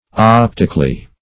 Optically \Op"tic*al*ly\, adv.